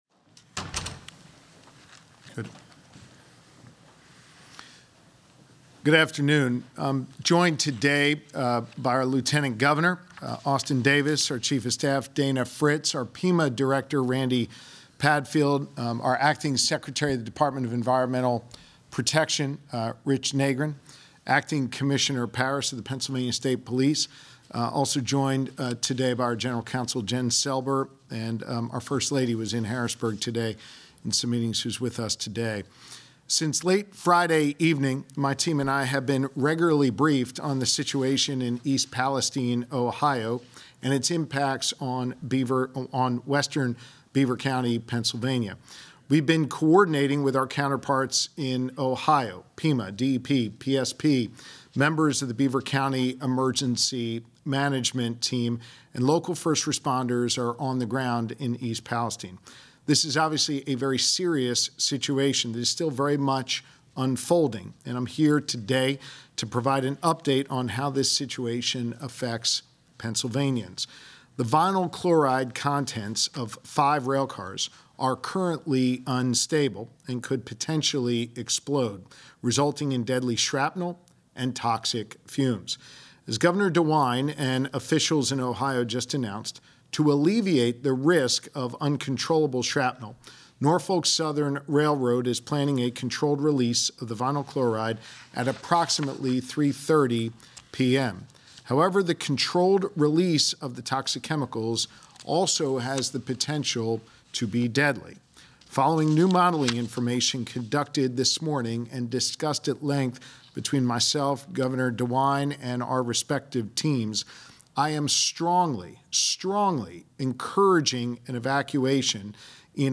Governor Shapiro Gives Urgent Update on East Palestine Train Derailment